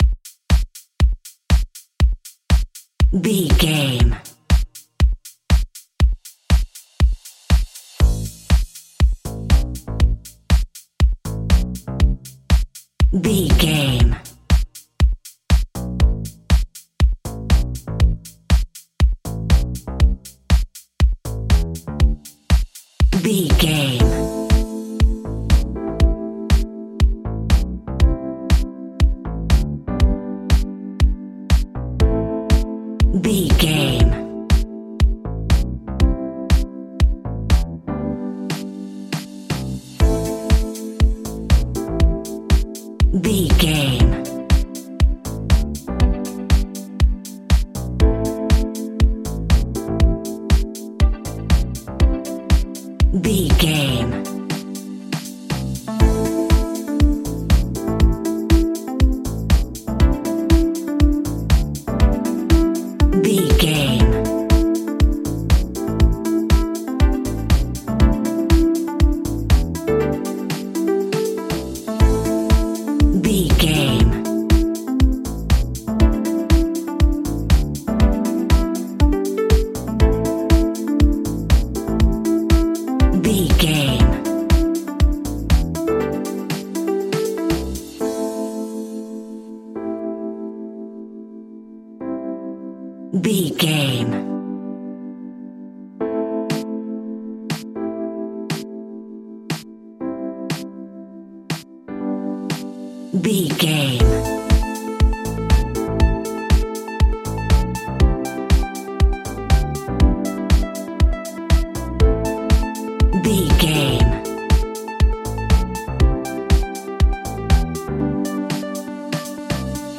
Aeolian/Minor
groovy
uplifting
driving
energetic
electric piano
bass guitar
synthesiser
drums
funky house
deep house
nu disco
upbeat
instrumentals